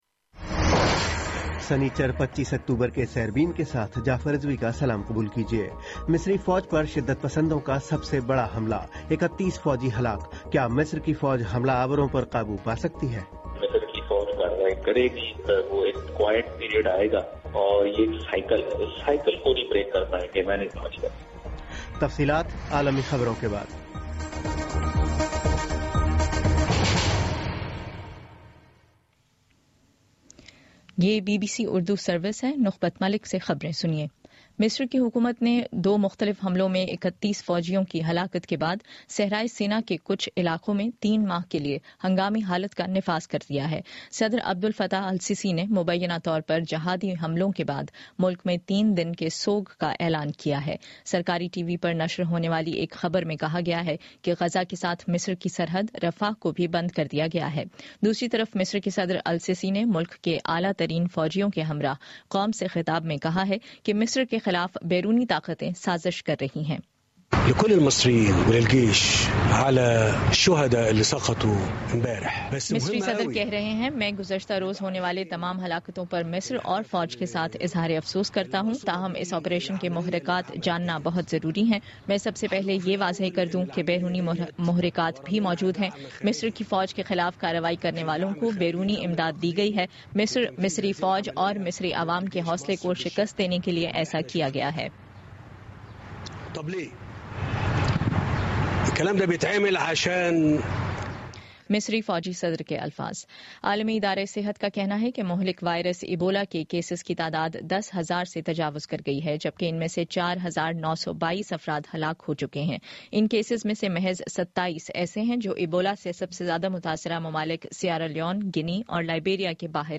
سنیچر25 اکتوبر کا سیربین ریڈیو پروگرام